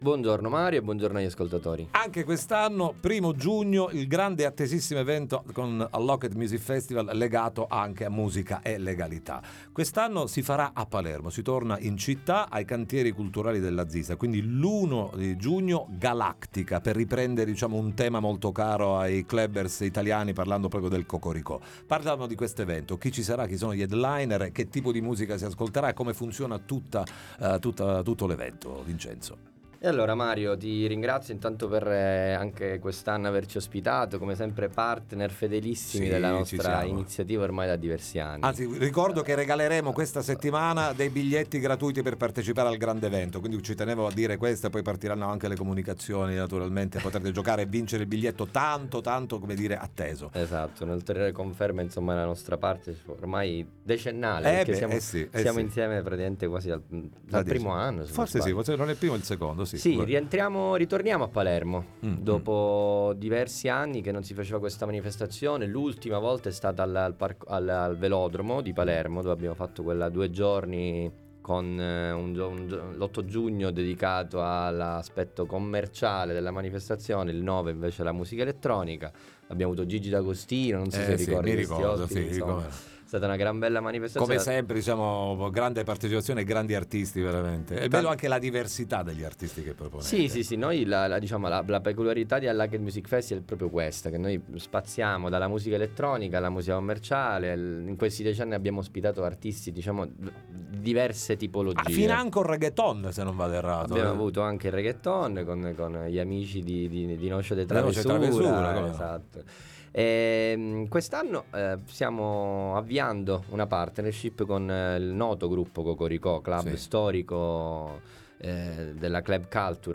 UNLOCKED MUSIC FESTIVAL X GALACTICA FESTIVAL intervista Time Magazine 27/05/2024 12:00:00 AM